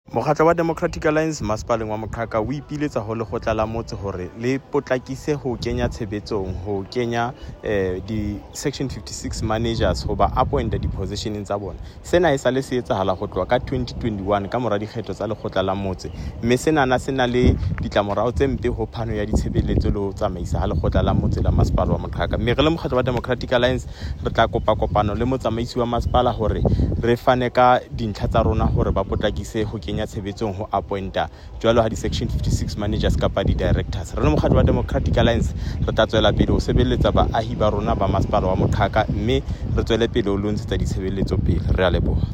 Sesotho soundbites by Cllr David Nzunga.